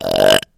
Звуки отрыжки
Звук рычания девушки